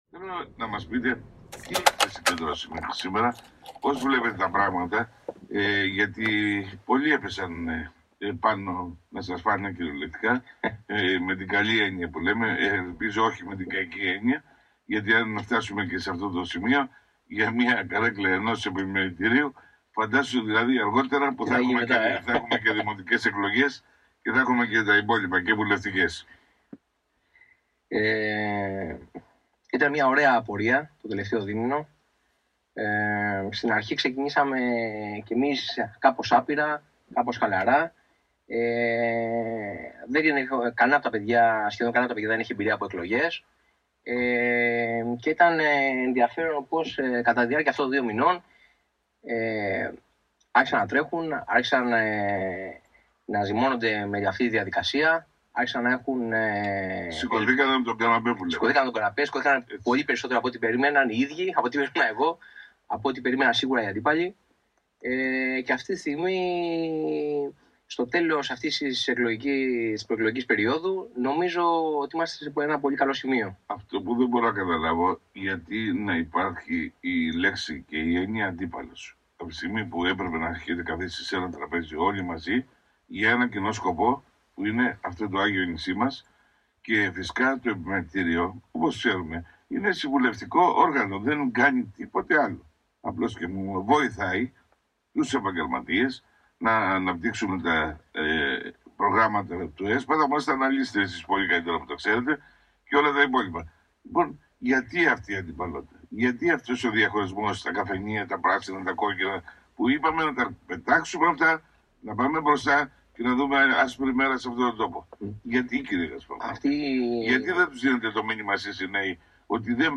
μία φορτισμένη συνέντευξή στο ραδιόφωνο